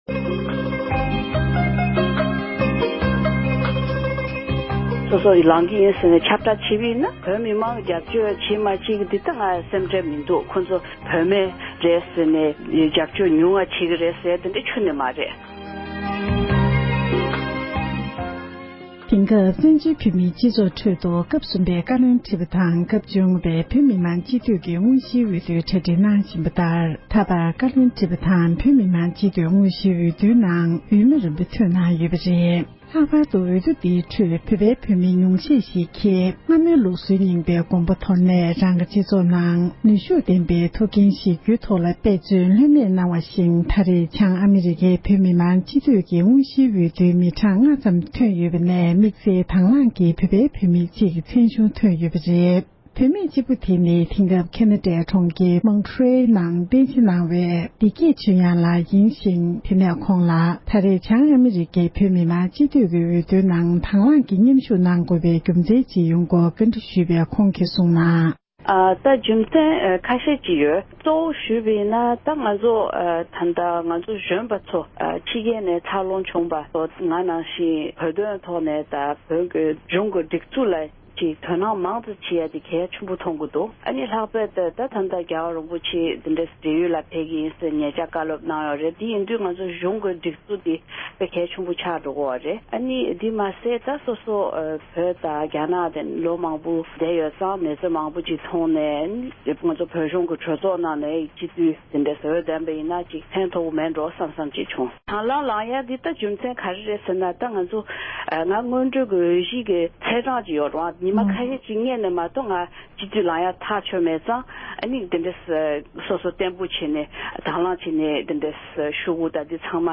ཞལ་པར་བརྒྱུད་ཐད་ཀར་གནས་འདྲི་ཞུས་པ་ཞིག